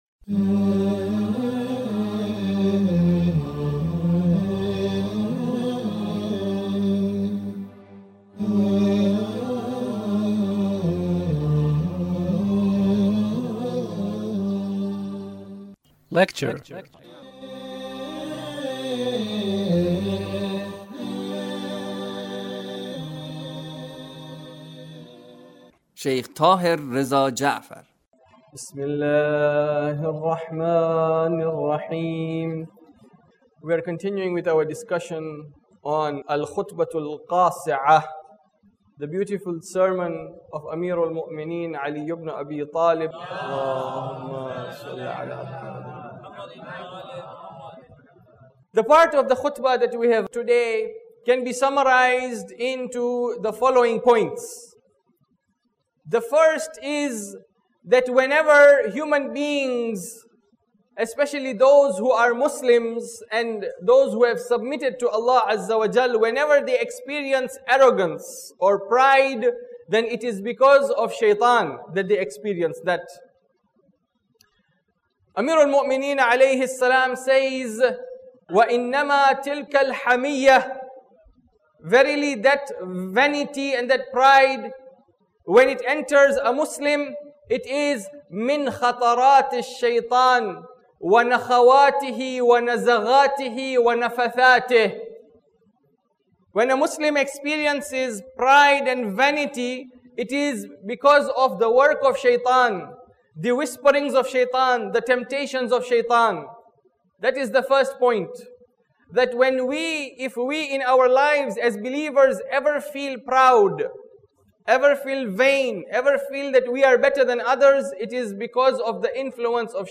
Lecture (33)